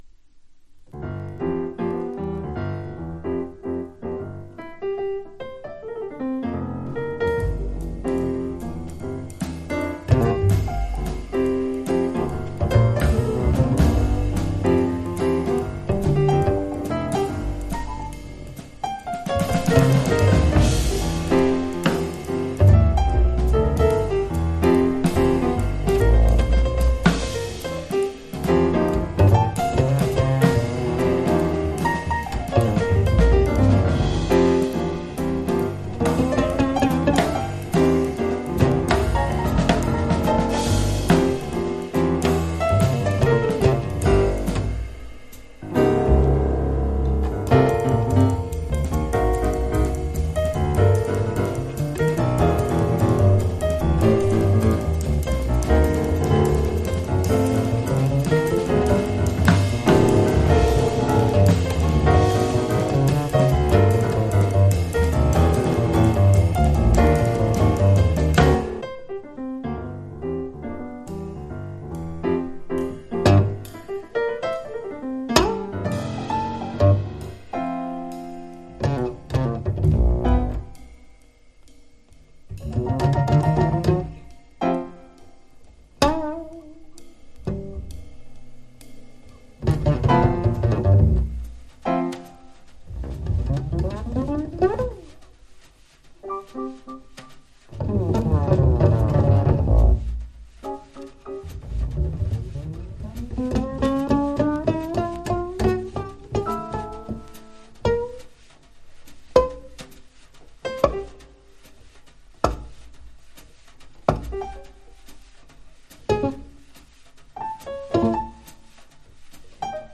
トリオもの好内容盤